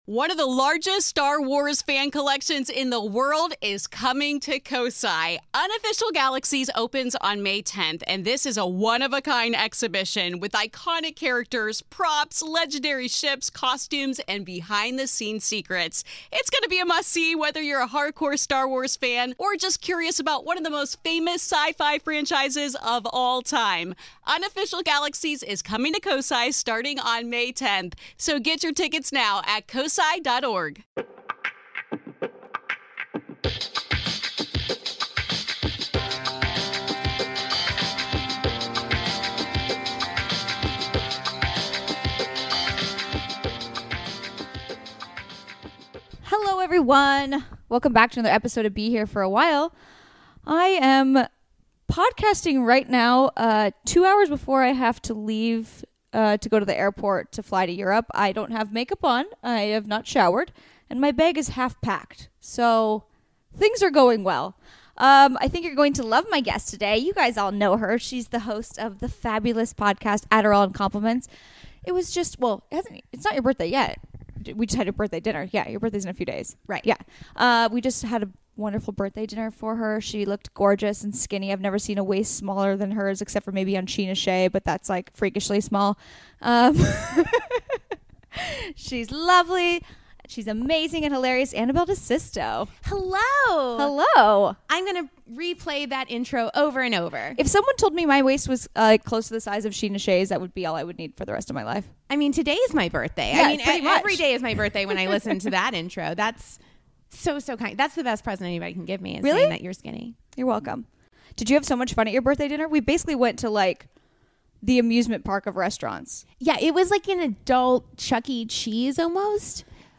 Education, Comedy, Comedy Interviews, Self-improvement